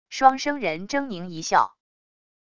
双生人狰狞一笑wav音频